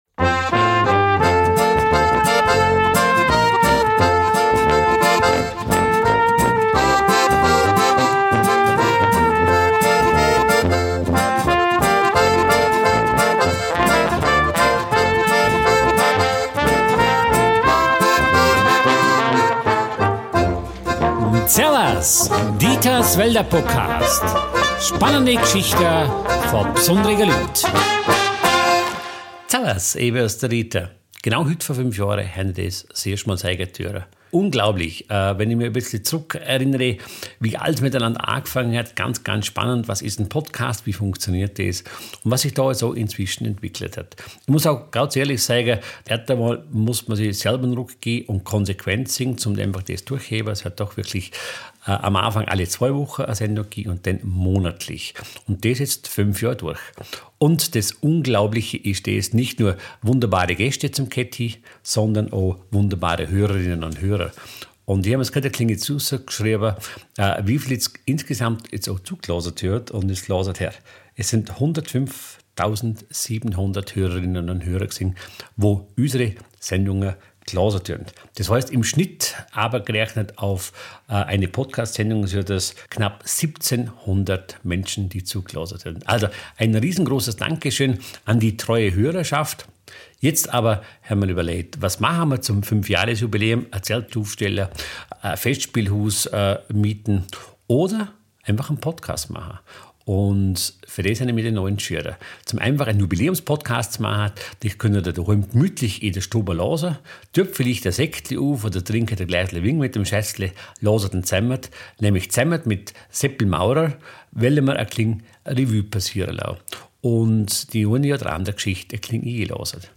Dieser Podcast ist mehr als ein Mikrofon im Keller.